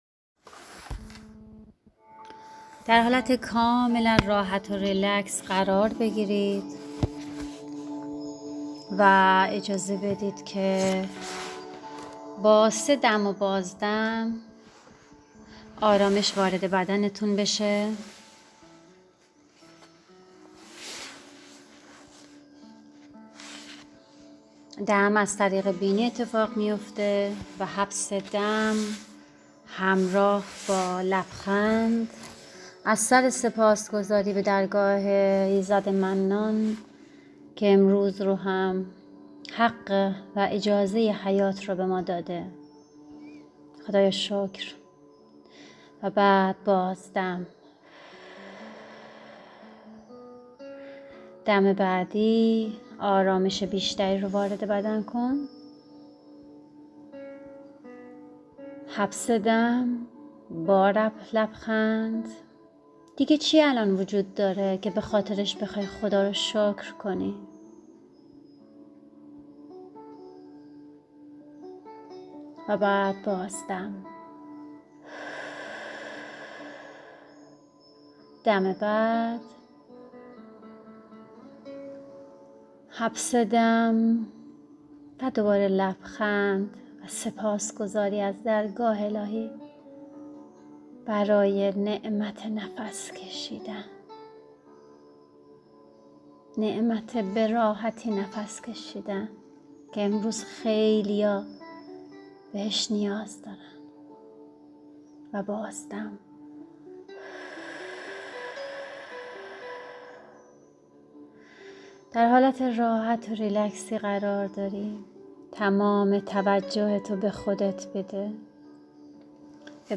مراقبه سپاس 1
جلسه 42 از کلاسهای پالایش روح و روان